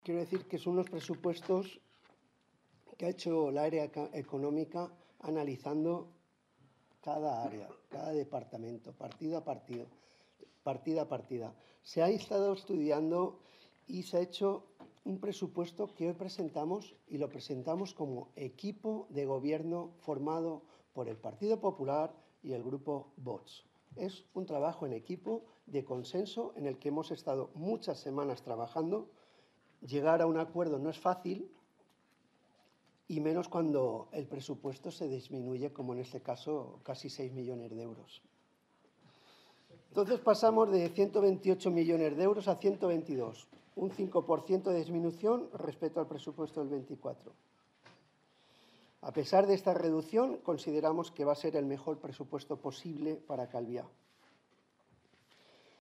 declaraciones-alcalde.mp3